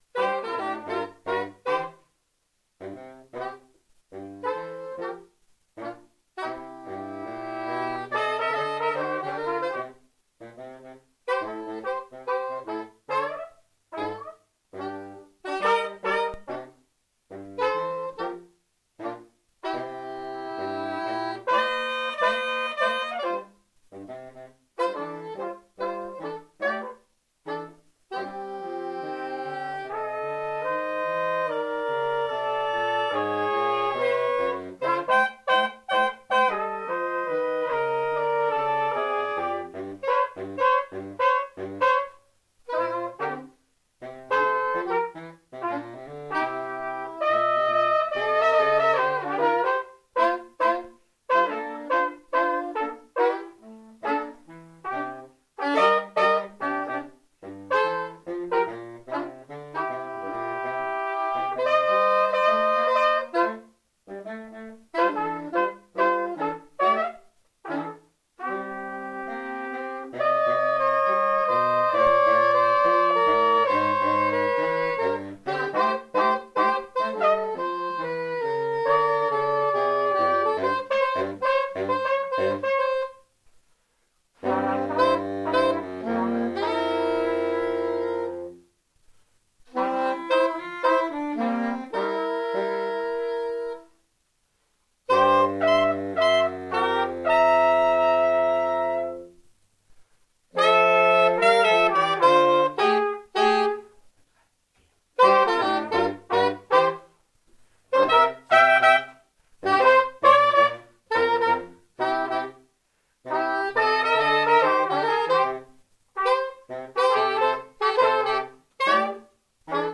· Genre (Stil): Jazz